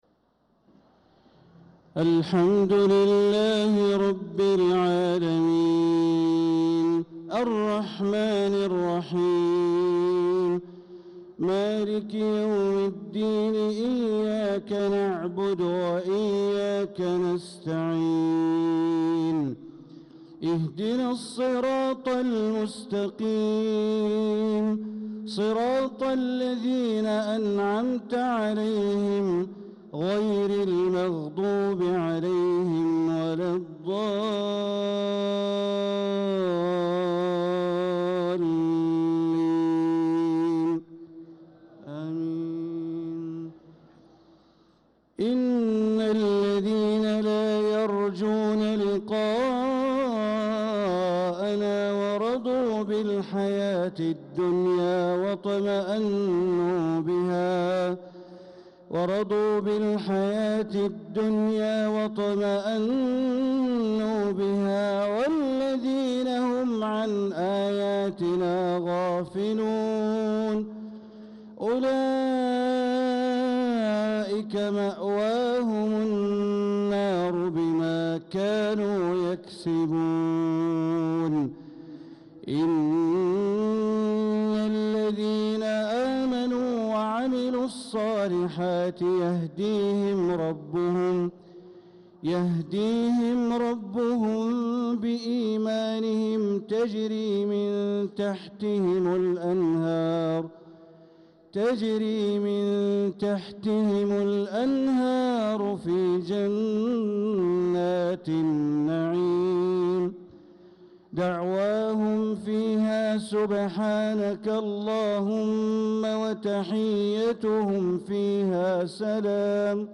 صلاة العشاء للقارئ بندر بليلة 13 ذو الحجة 1445 هـ